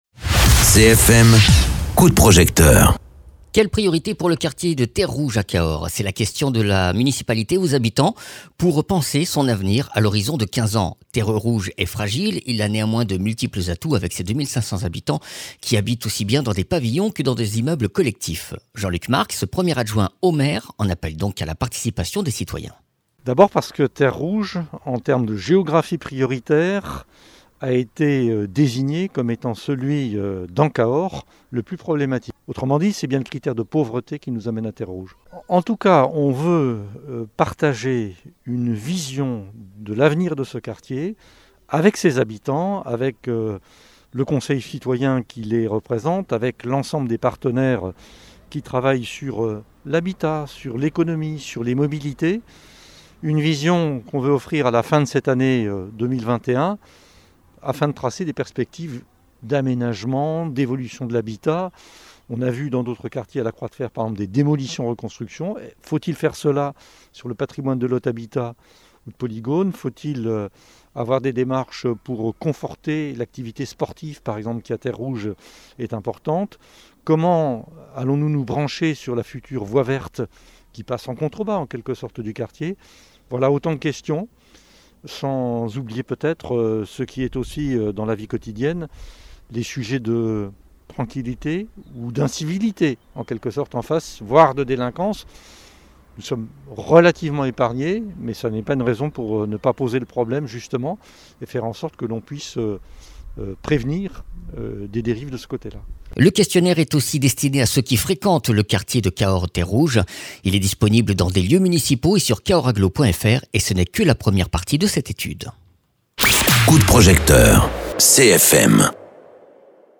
Interviews
Invité(s) : Jean Luc Marx, premier adjoitnau Maire de Cahors